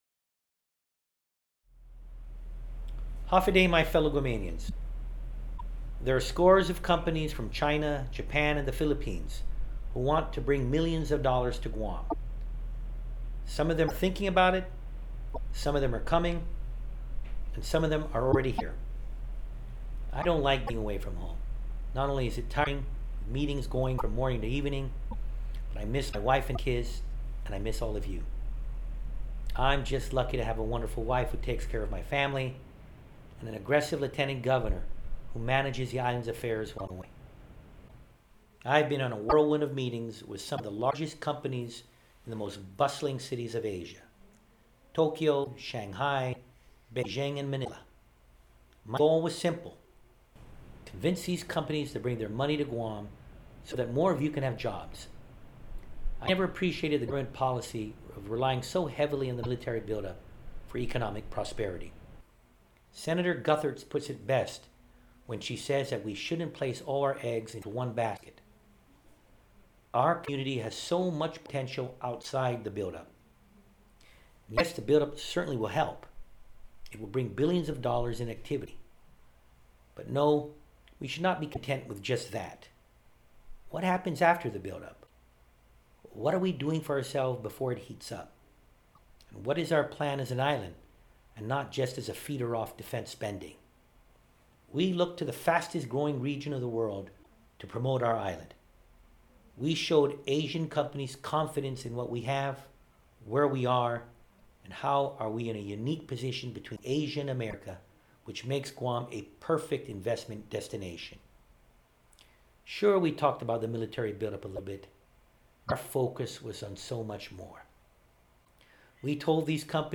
Governor's Weekly Radio Address: Oct 31